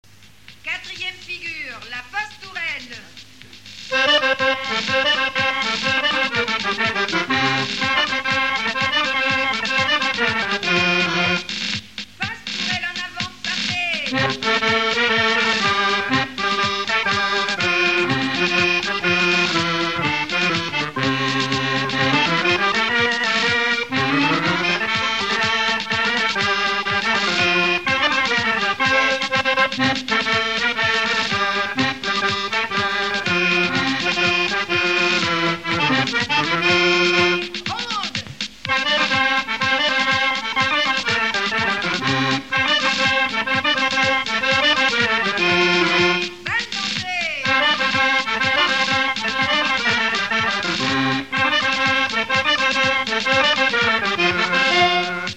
Localisation Nalliers ( Plus d'informations sur Wikipedia ) Vendée
Fonction d'après l'analyste danse : quadrille : pastourelle ;
Catégorie Pièce musicale inédite